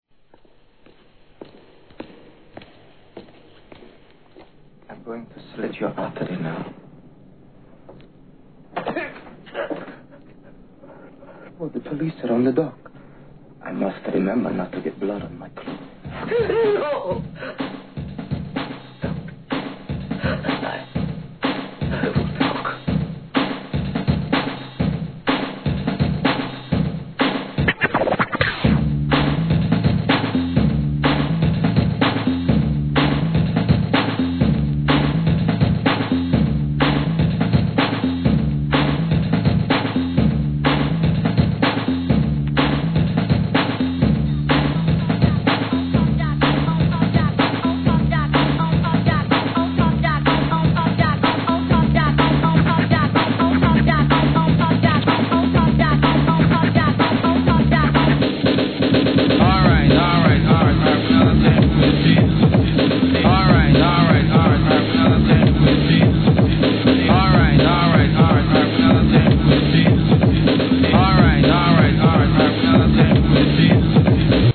1998年、イギリス産ブレイク・ビーツ!!